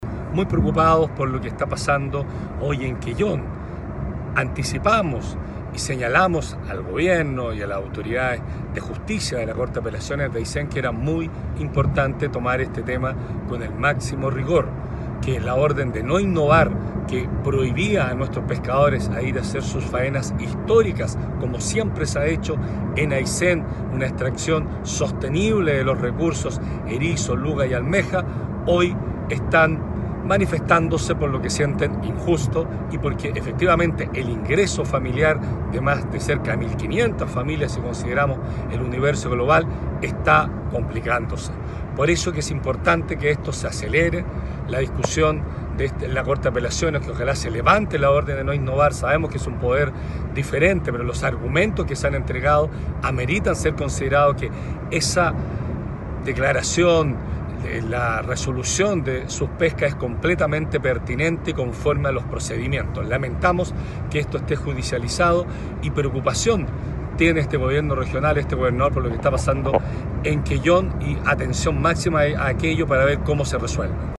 El gobernador regional Patricio Vallespín lamentó que la causa por la cual protestan los pescadores de la zona junto a otras organizaciones, se haya judicializado, causando gran incertidumbre en las familias que dependen de estos recursos, que ahora están impedidos de extraer y comercializar.
19-GOBERNADOR-PATRICIO-VALLESPIN-PARO-QUELLON.mp3